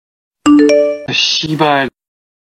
Genre: Nada dering Korea